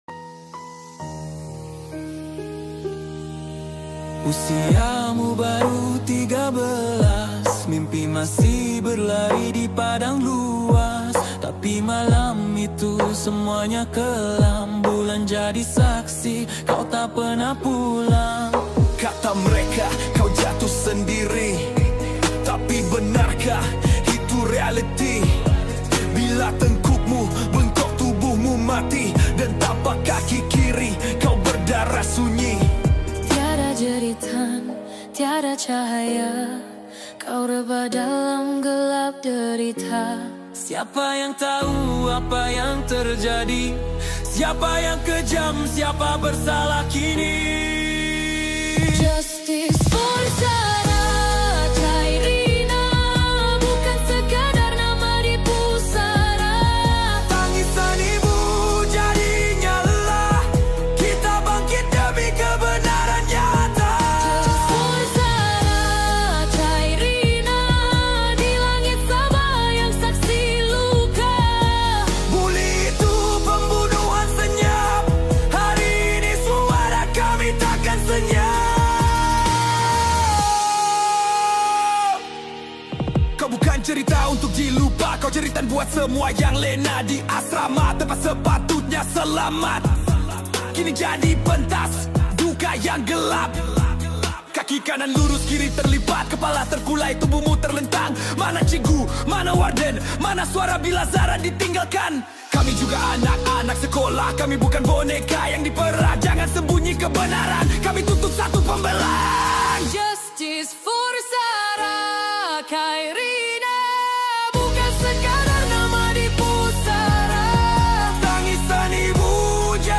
Patriotic Songs
Malay Patriotic Song
Skor Angklung